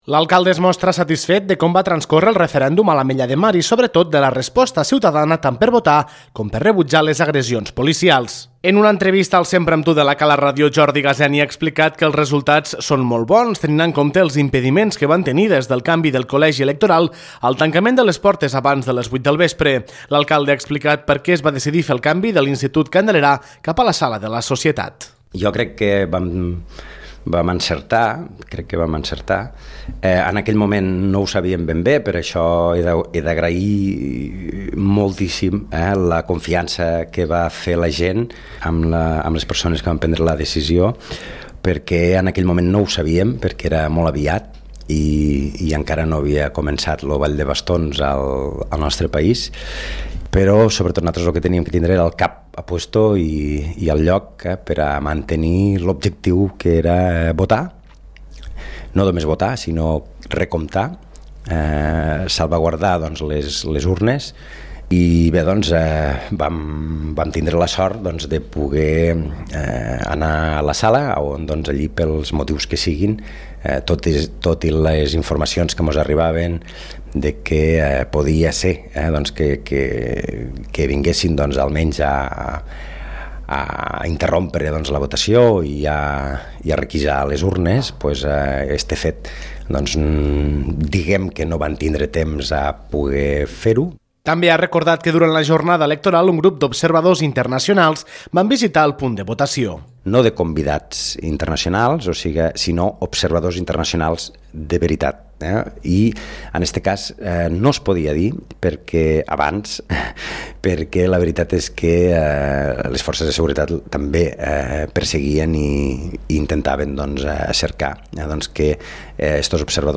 En una entrevista al Sempre amb tu, Jordi Gaseni ha explicat que els resultats són molt bons, tenint en compte els impediments que van tenir, des del canvi del col·legi electoral al tancament de les portes abans de les 20 h.